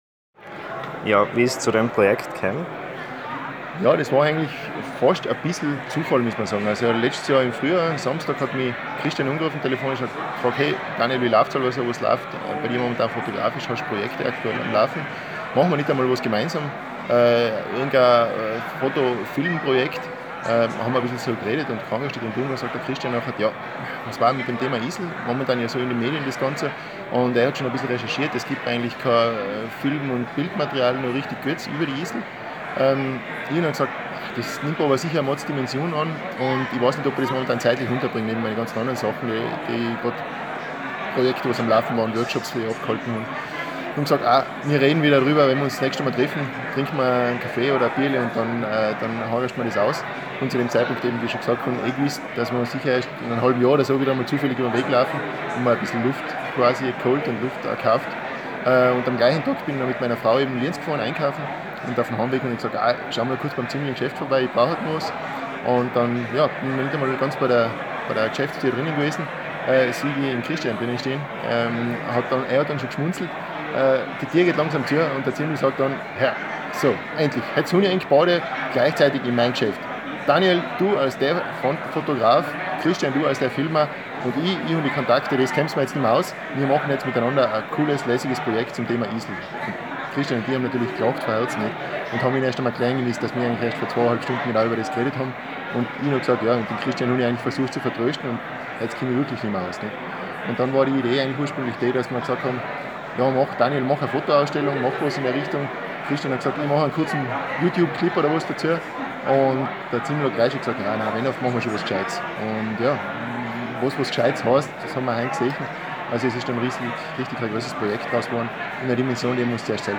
Mit Slideshow und Audiointerviews!
Als er endlich abklingt, bitte ich die drei Filmproduzenten und Ingrid Felipe vor das Mikrofon: